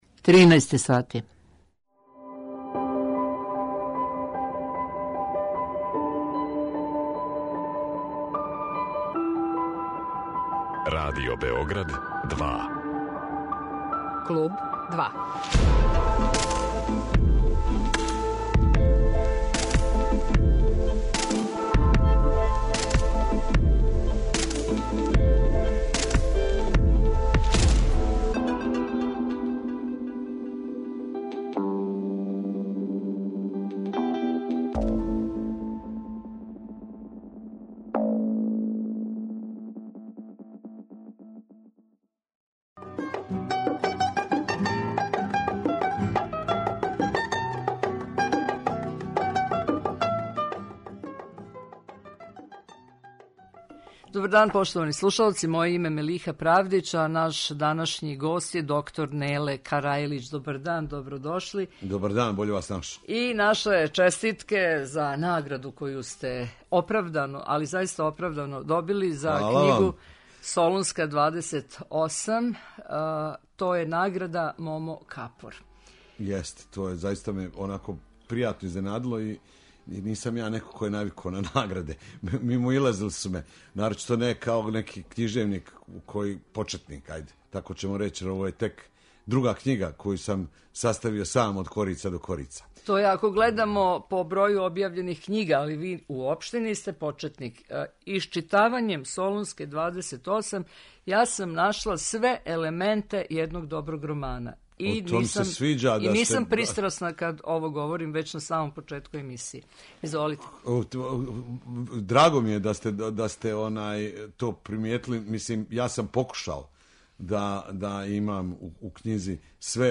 Гост 'Клуба 2' је др Неле Карајлић (Ненад Јанковић) аутор књиге 'Солунска 28' за коју је добио Награду 'Момо Капор'